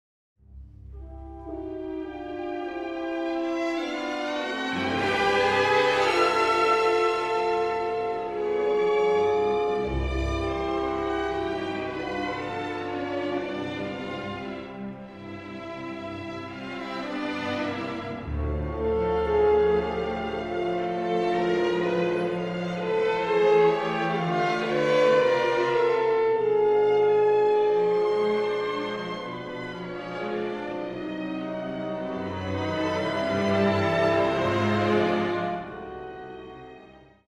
Pierwszy temat brzmi w tej stylistyce bardzo dobrze, grany jest mocno, pewnie, a blacha brzmi znakomicie – czysto i precyzyjnie. Nadspodziewanie ostre jest wejścia tematu drugiego, gdzie Solti wyraźnie zaakcentował łamane akordy w sekcji smyczków: